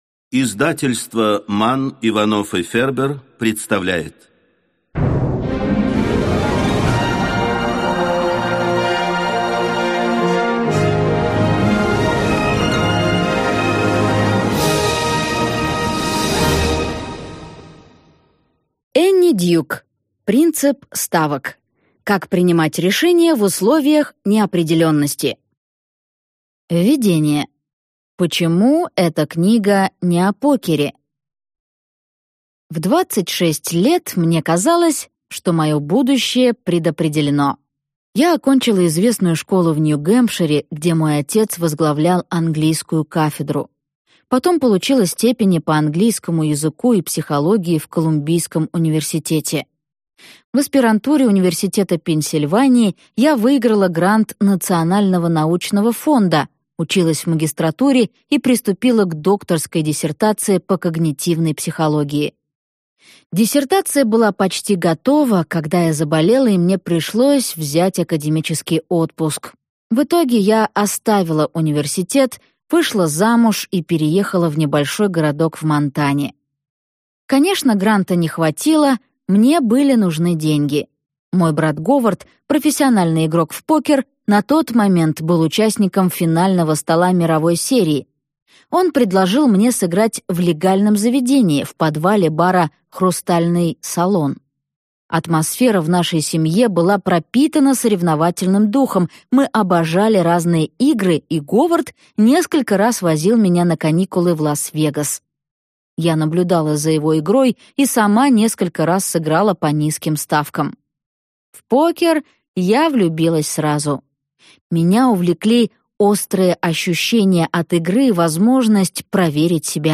Аудиокнига Принцип ставок | Библиотека аудиокниг